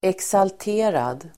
Ladda ner uttalet
Uttal: [eksalt'e:rad]